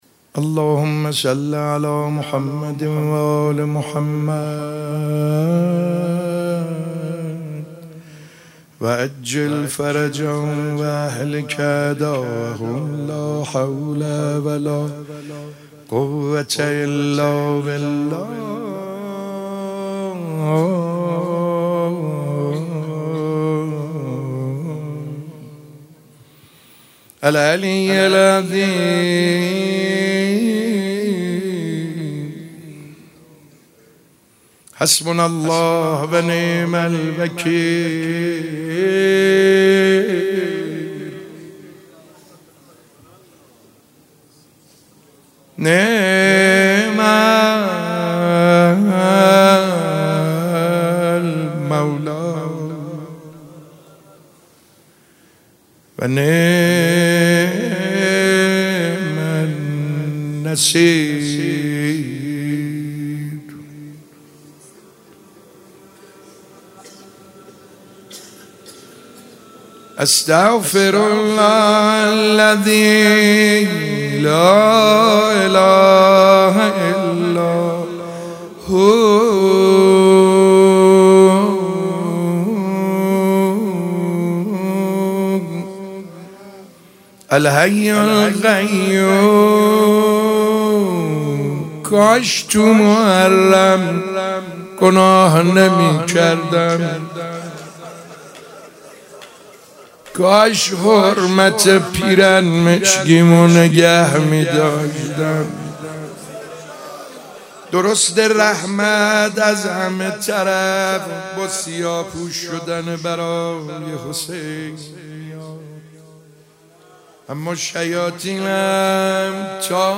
روضه حضرت زینب سلام الله علیها